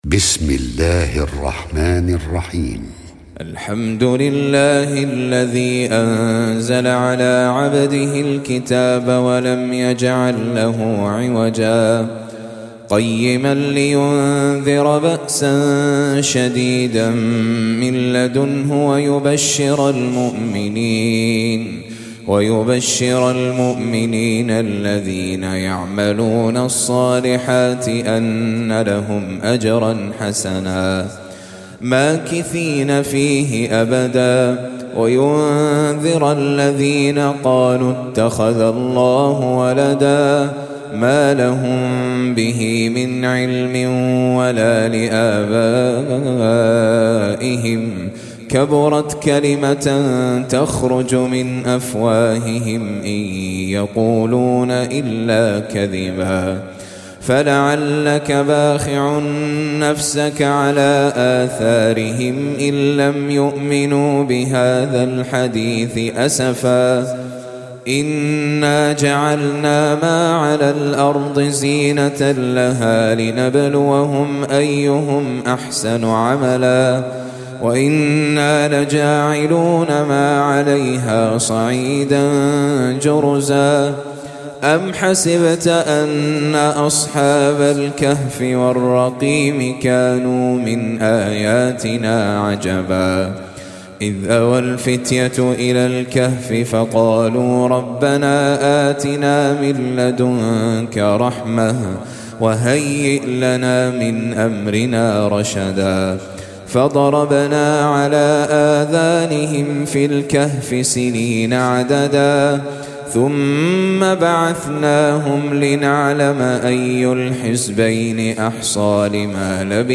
18. Surah Al-Kahf سورة الكهف Audio Quran Tajweed Recitation
Surah Sequence تتابع السورة Download Surah حمّل السورة Reciting Murattalah Audio for 18. Surah Al-Kahf سورة الكهف N.B *Surah Includes Al-Basmalah Reciters Sequents تتابع التلاوات Reciters Repeats تكرار التلاوات